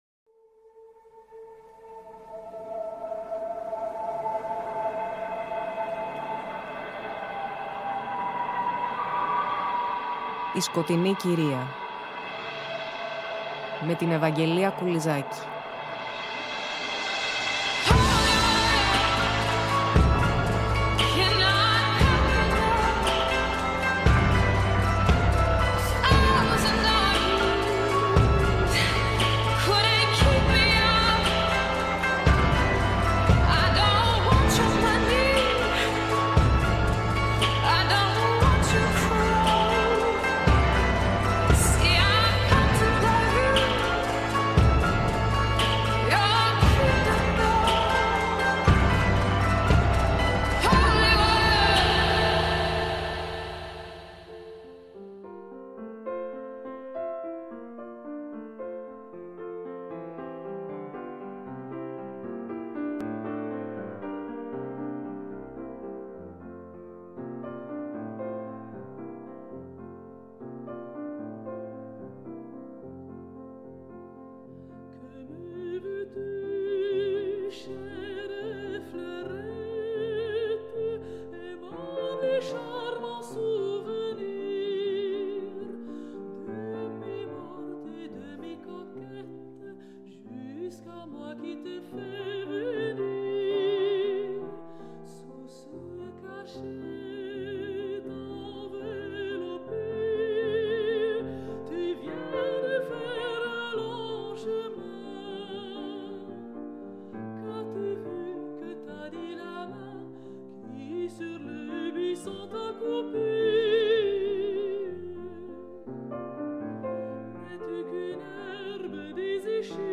Στο μουσικό φόντο τραγούδια σε στίχους του Musset και φυσικά συνθέσεις του Chopin.